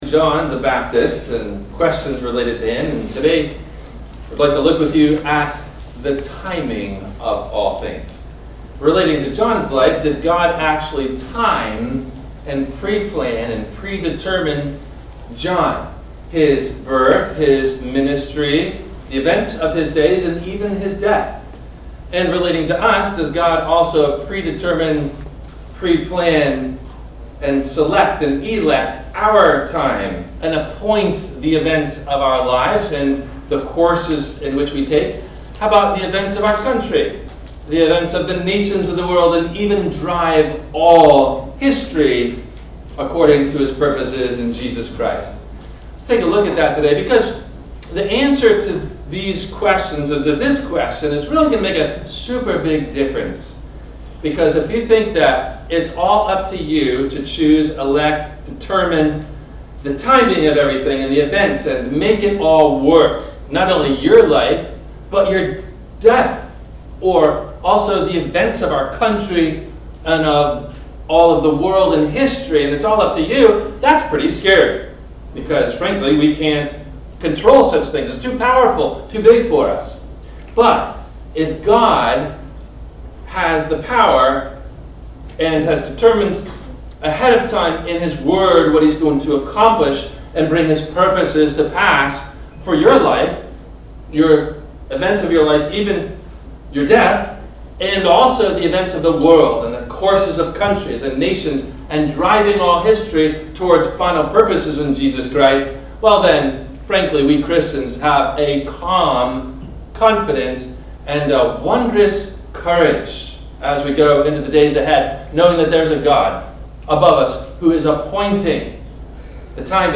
Has God Planned The Events And Timing Of History? – WMIE Radio Sermon – December 19 2022